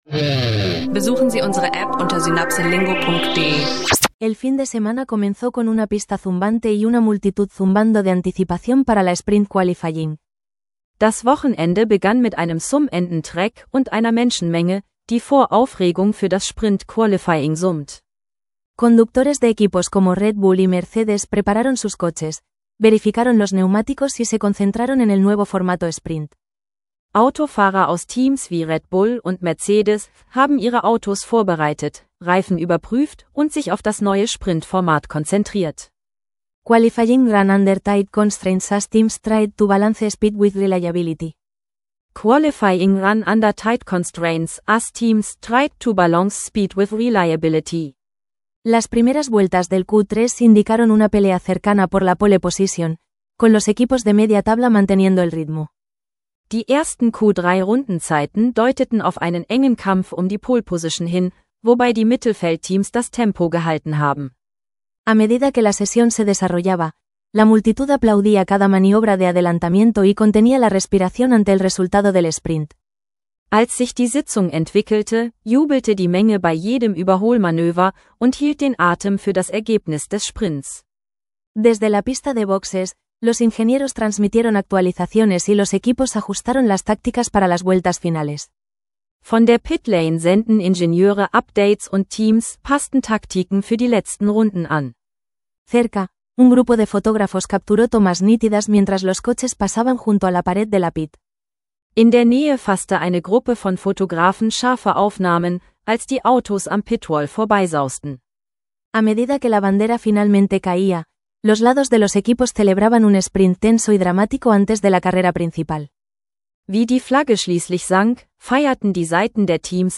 Erlebe eine lebendige Sprint-Qualifying-Sitzung im F1-Drama und lerne dabei Spanisch – praxisnaher Audio-Sprachkurs.